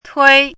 “忒”读音
tuī
tuī.mp3